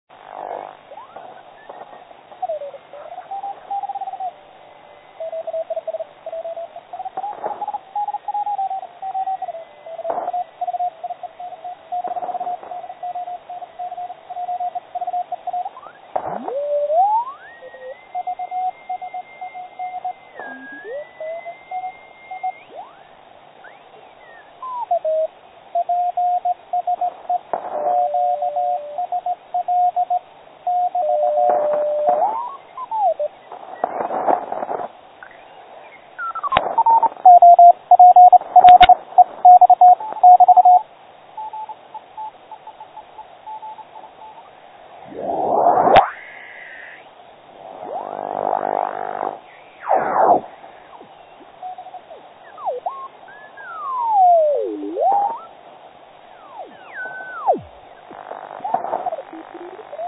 O to více zarážející je poslech na přímosměši s fázově potlačeným druhým záznějem.